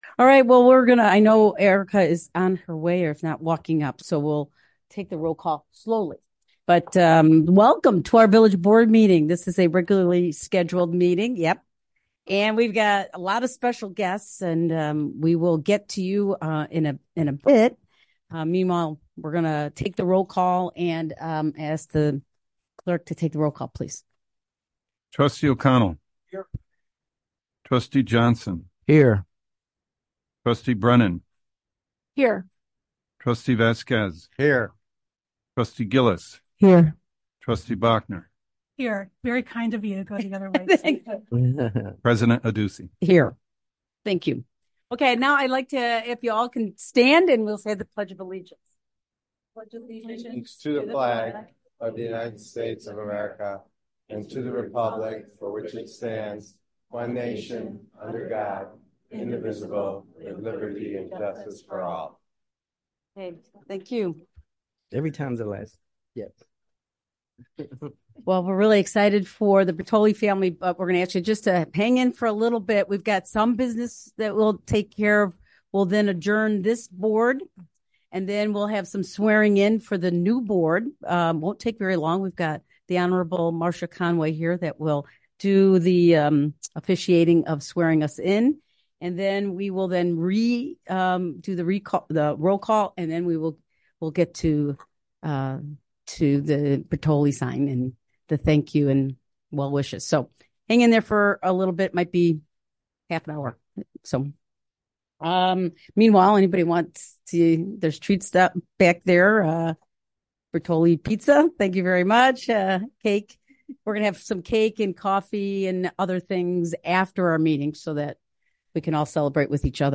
Village Board of Trustees Meeting
Village Hall - 1st Floor - COMMUNITY ROOM - 400 Park Avenue - River Forest - IL